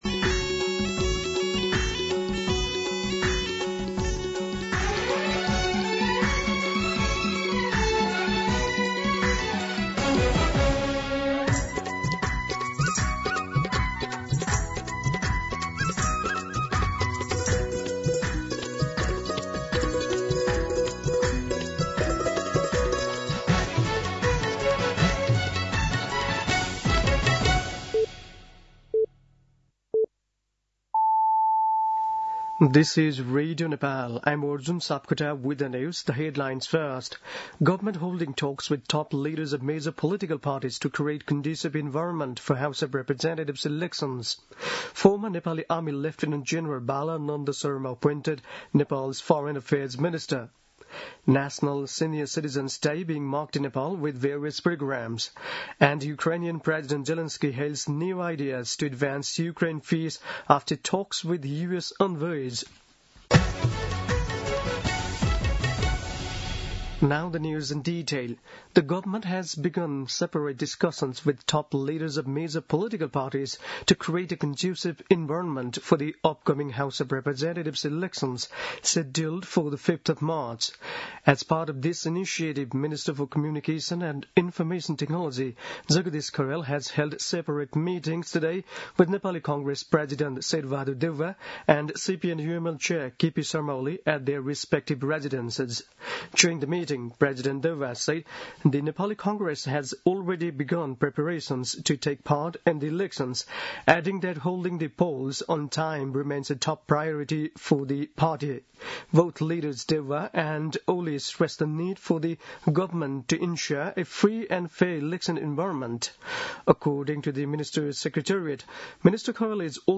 दिउँसो २ बजेको अङ्ग्रेजी समाचार : ११ पुष , २०८२